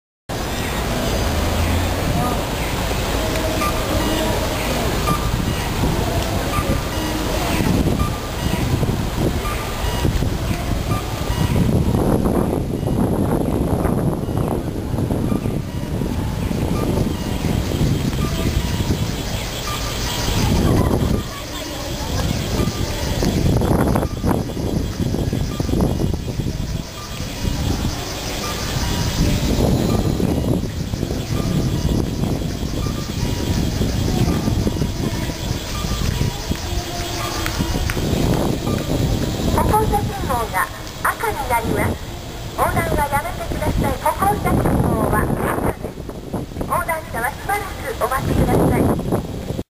3回線は熊本市を回りました。
カッコーです。
音程が少しずれているような気がするので、こちらは名電と分かりました。